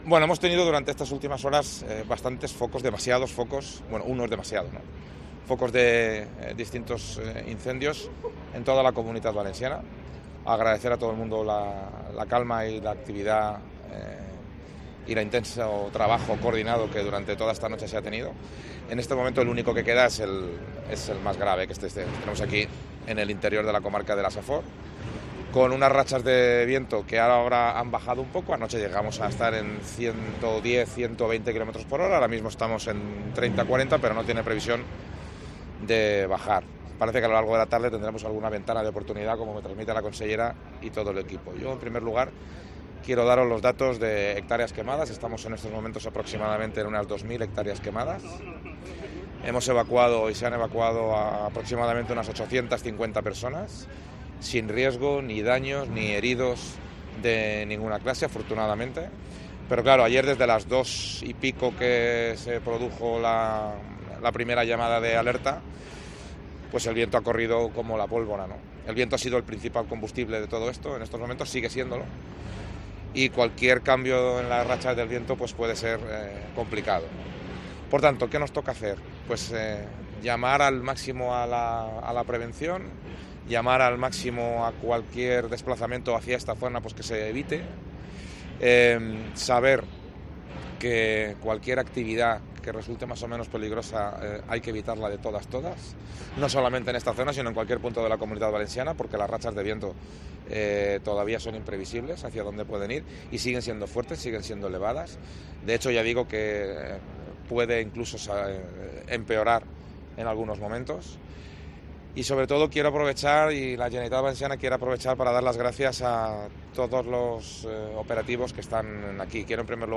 Comparecencia de Carlos Mazón desde el Puesto de Mando Avanzado del incendio de Montitxelvo